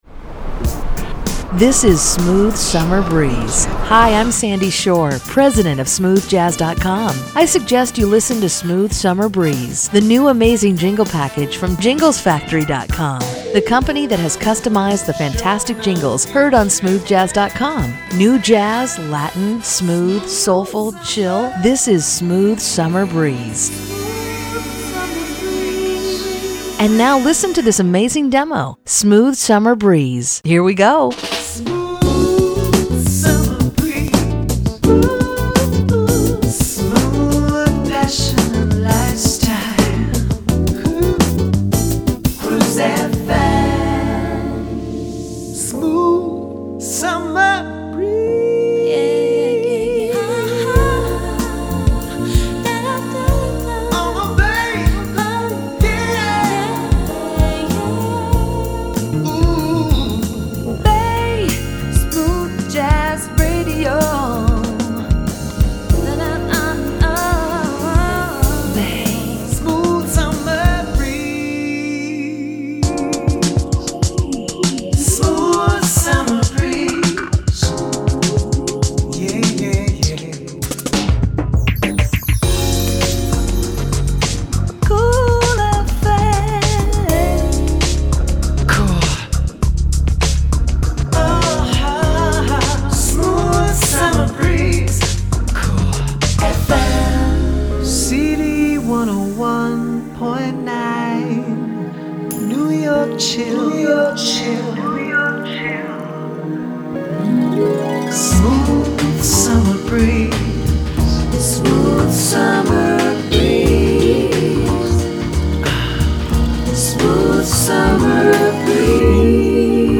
Pop, CHR, AC, Smooth/Latin Jazz, Dance etc…